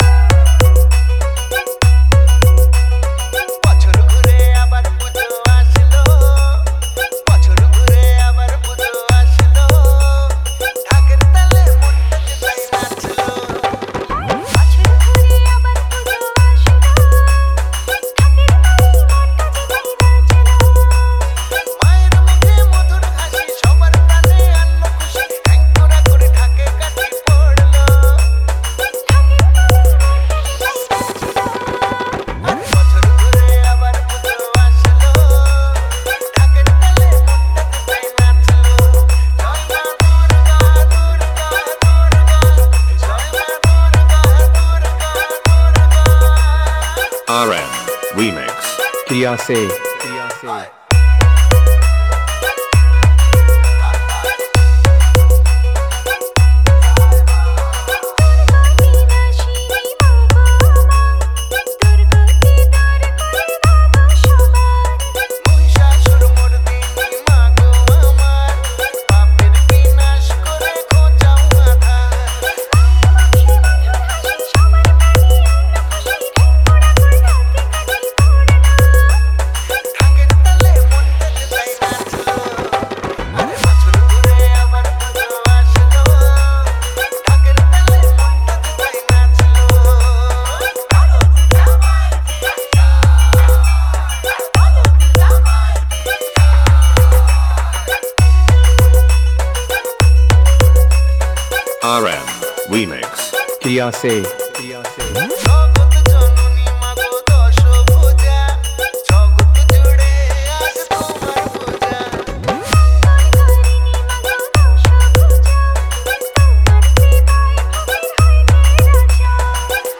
দুর্গ উৎসব স্পেশাল বাংলা নতুন স্টাইল ভক্তি হামবিং মিক্স 2024